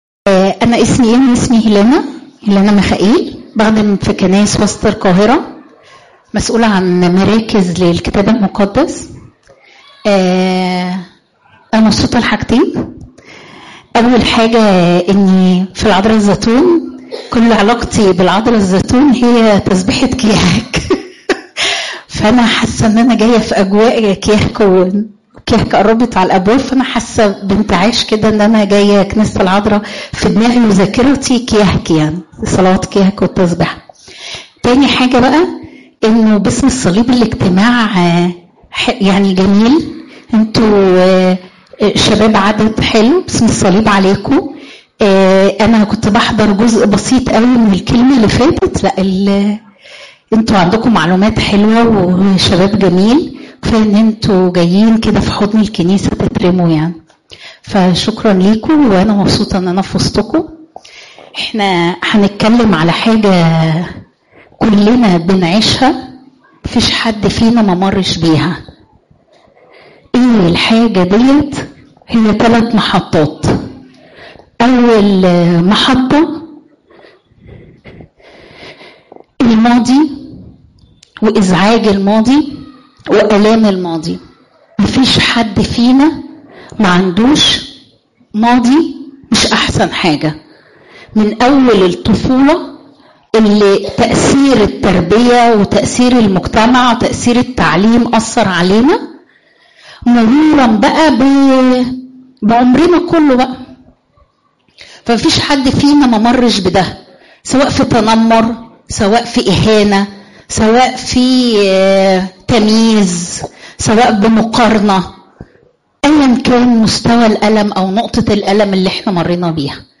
تفاصيل العظة
إجتماع الصخرة للشباب الخريجين